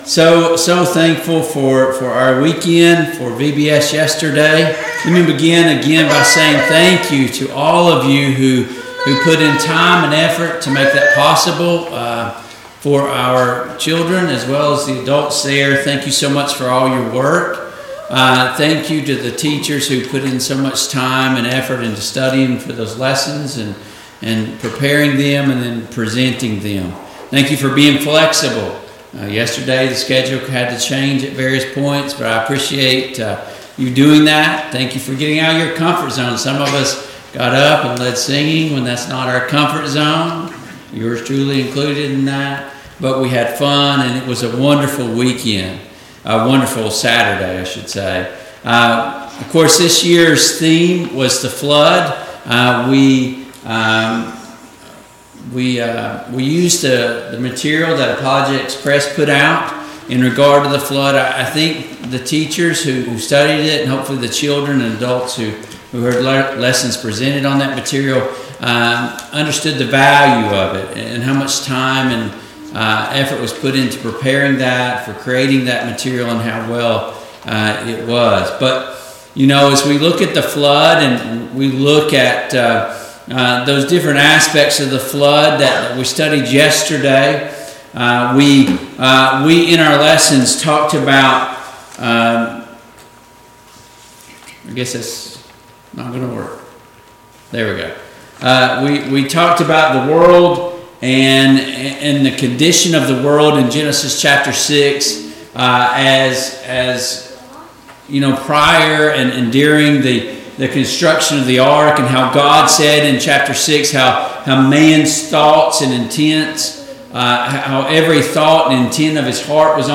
2021 Vacation Bible School Service Type: AM Worship Download Files Notes Topics: Noah and the Flood « 4.